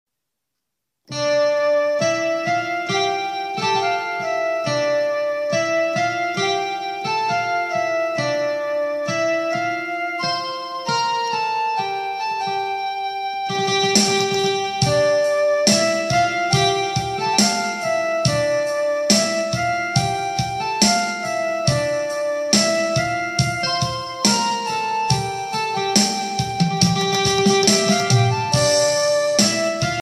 Chinese music